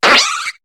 Cri de Mascaïman dans Pokémon HOME.